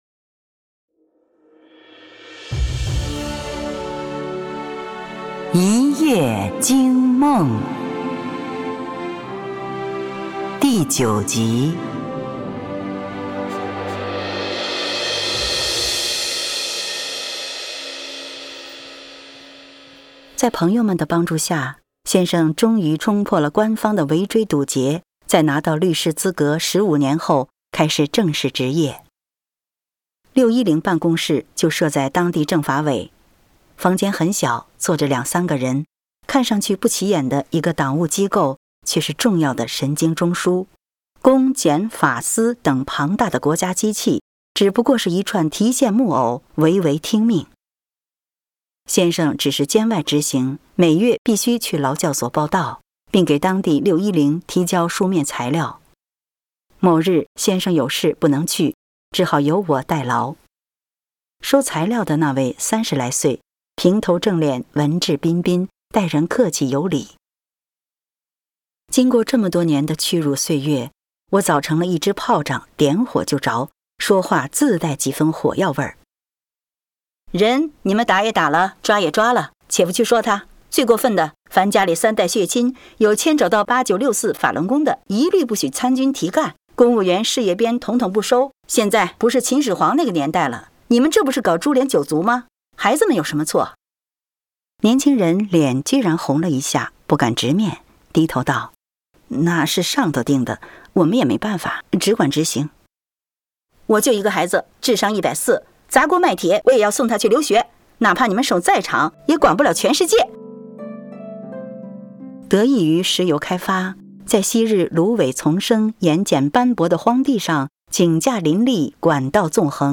紀實散文連播（音頻）：一夜驚夢（09完） | 法輪大法正見網